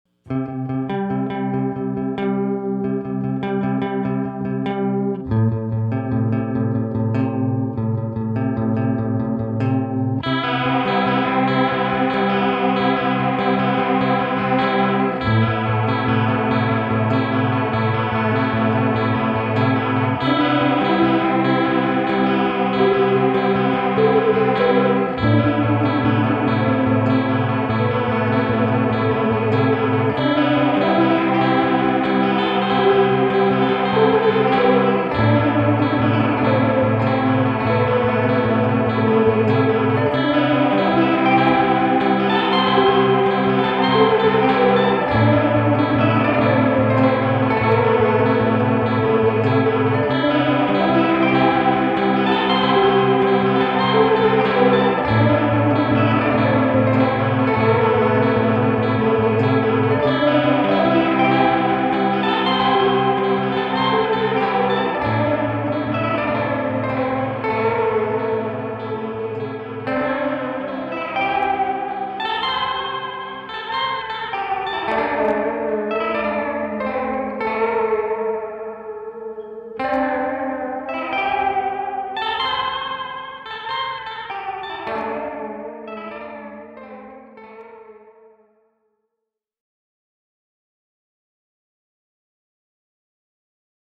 the sea machine is a chorus pedal with ultimate control over parameters rarely, if ever, seen in a chorus before. a hybrid of digital and analog circuitry with a slightly extended delay time allows it to really stand out and shimmer. subtle warble, classic leslie, sea sick pitch bends, strangled aliens, stunted arpeggiations... there is something for everyone in the sea machine. designed to work well following fuzz, distortion or overdrive without getting muddy, loss of volume or breaking up (the sea machine has excellent headroom at both 9 & 12 volts). when engaged, the transparent buffer leaves the all analog dry signal unaltered and crystal clear. it can be powered from 9-12v dc, higher voltage yields a more intense modulation, slightly longer delay time with more chime and dimension. the sea machine is true bypass and made by human hands with the highest quality parts in lovely akron ohio.
tele deluxe > sea machine > looper > deluxe reverb > garage band